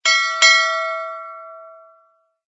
SZ_DD_shipbell.ogg